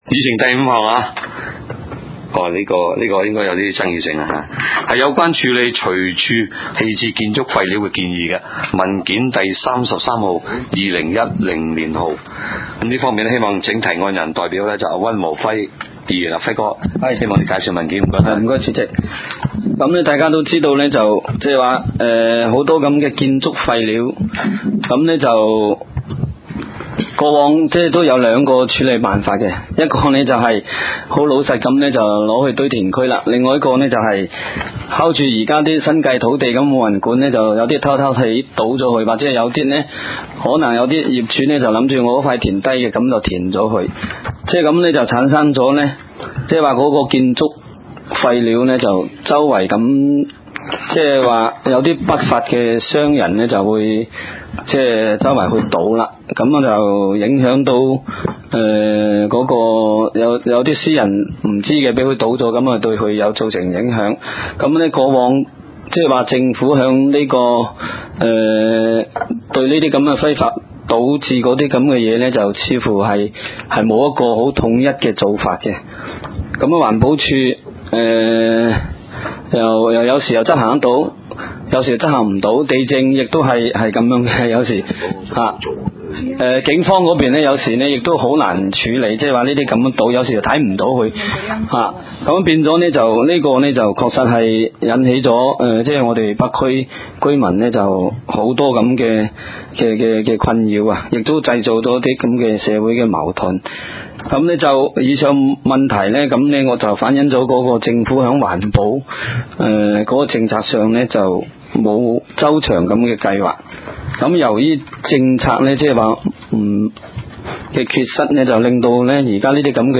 地區小型工程及環境改善委員會第15次會議